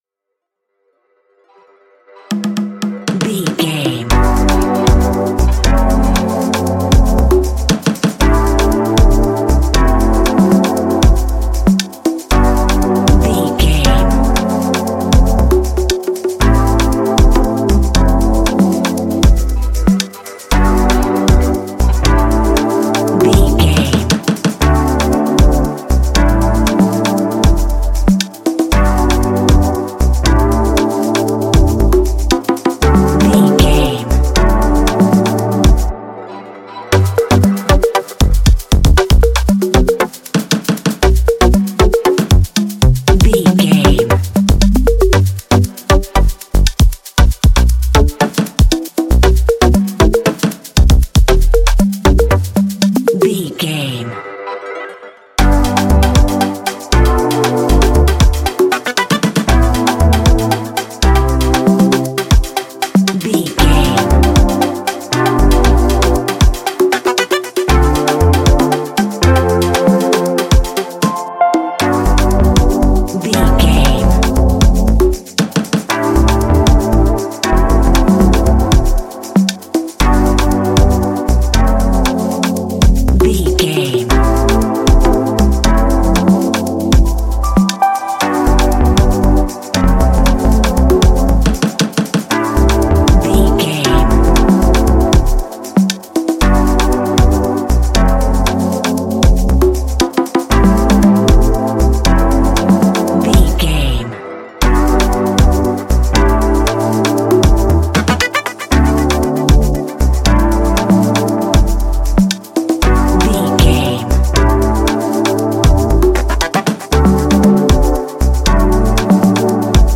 Ionian/Major
afrobeat
Afro synth
percussion
tribal
instrumental
Warm atmosphere